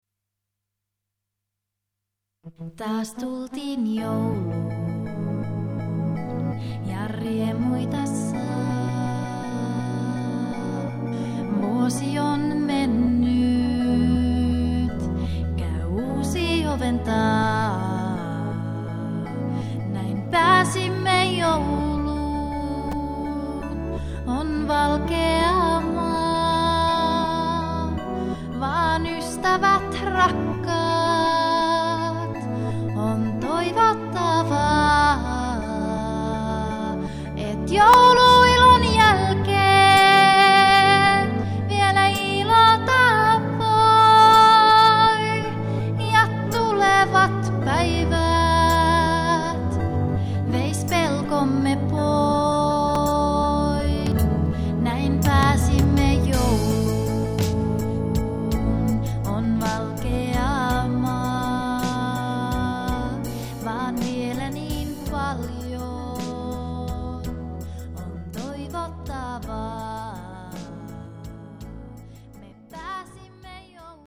laulut
kitarat
Äänitys on tehty kotistudiossa Kemissä.
Muut instrumentit on ohjelmoitu MIDI-tekniikalla.